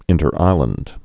(ĭntər-īlənd)